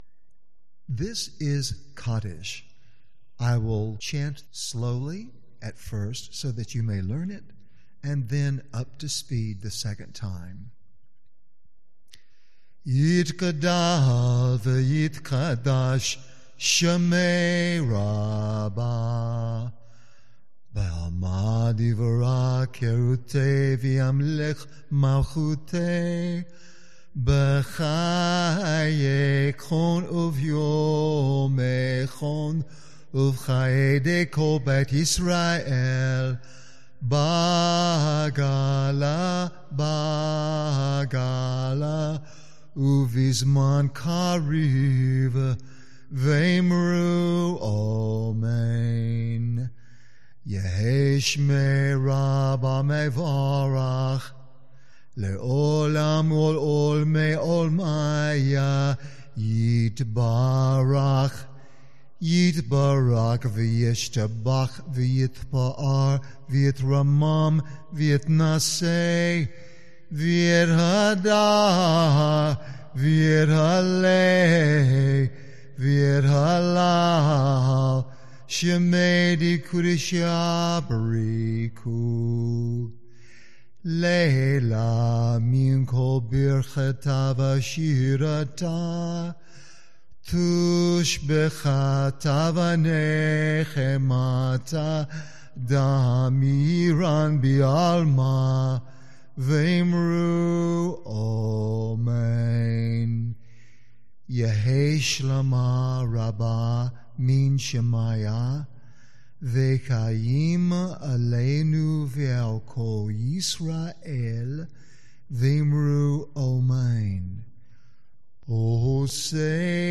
These are the common blessings and prayers we recite during our Shabbat service at Beth El Shaddai. We offer them here for you to listen and learn them so that you may also do them with us, the way we do them in during service.
06-Kaddish-Chant.mp3